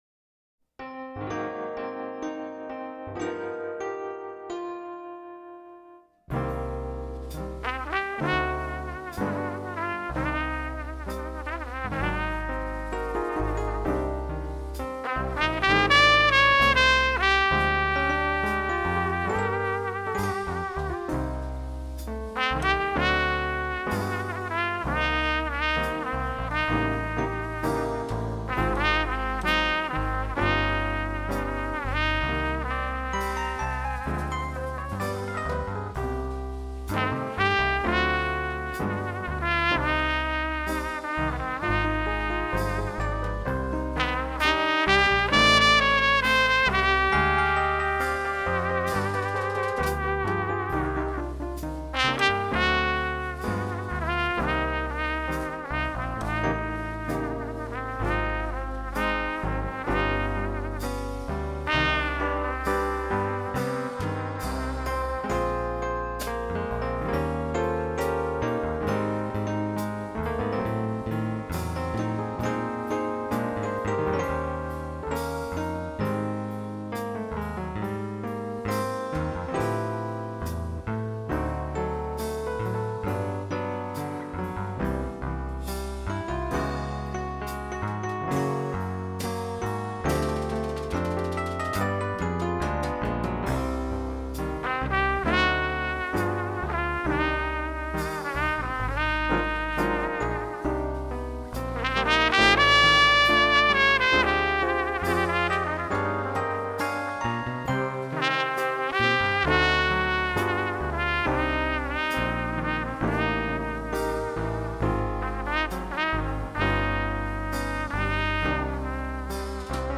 Jazz Ballad